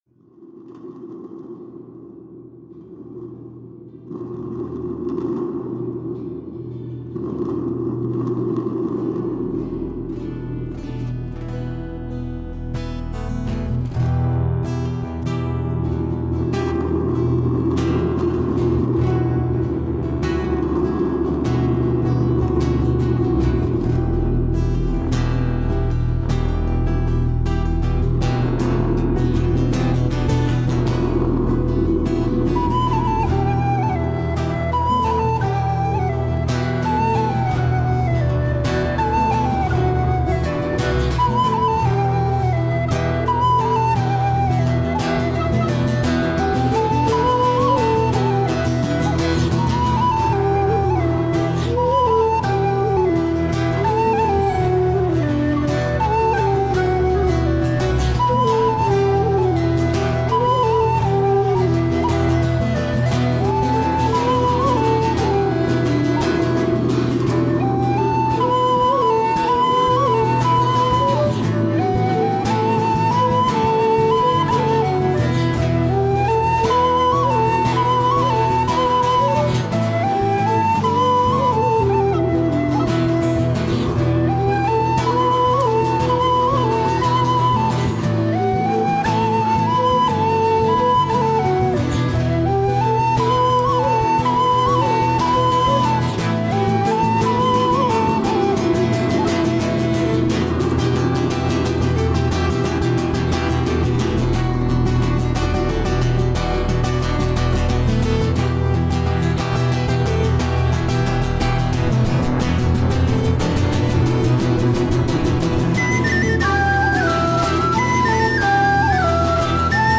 Brian Ború's March Audios (6/8)